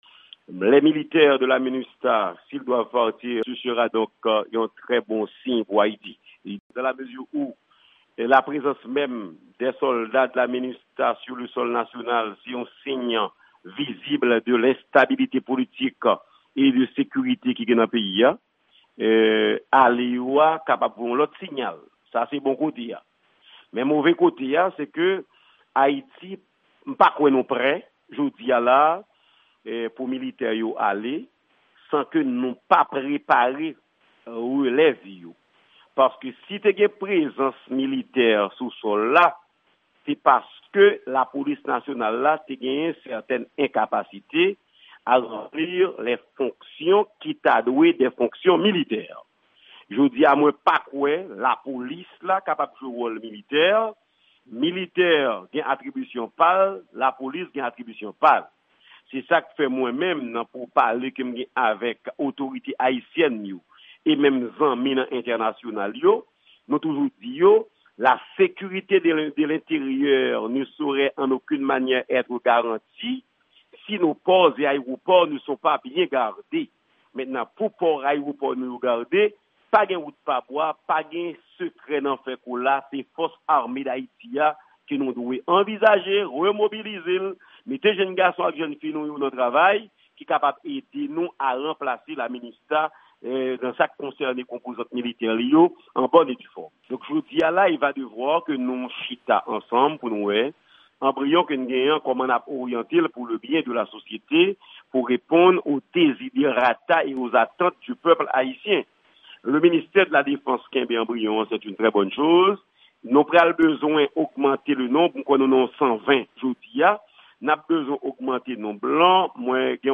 Deklarasyon Senatè Jean Renel Senatus nan mikwo Lavwadlamerik